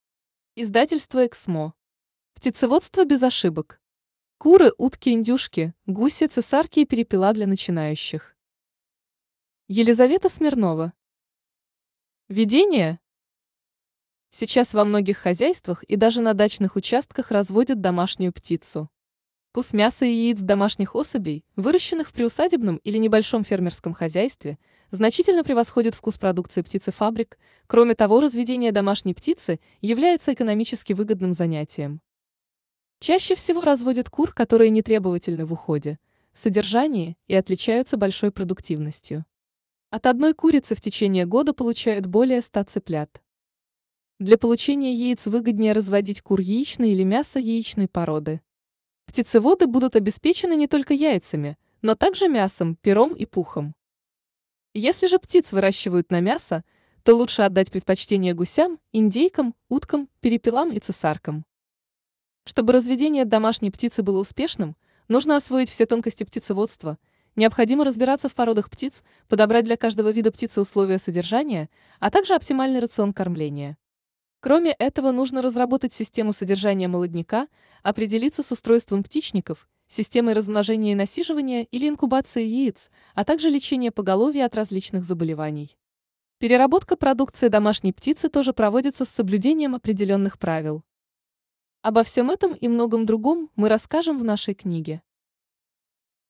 Аудиокнига Птицеводство без ошибок. Куры, утки, индюшки, гуси, цесарки и перепела для начинающих | Библиотека аудиокниг
Читает аудиокнигу Искусственный интеллект